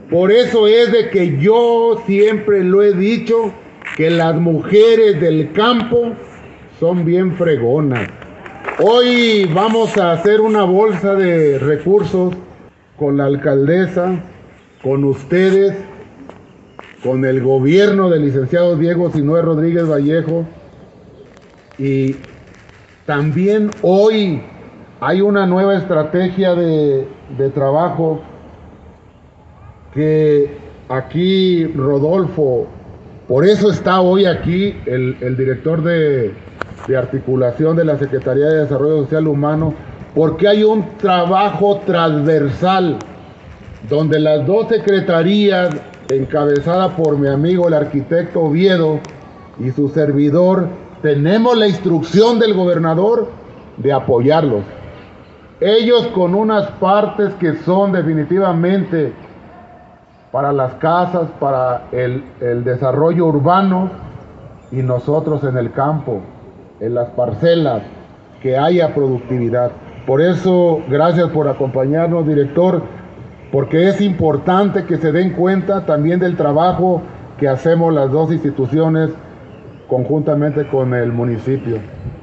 AudioBoletines
Lorena Alfaro García – Presidenta Municipal
Paulo Bañuelos Rosales – Secretario de Desarrollo Agroalimentario y Rural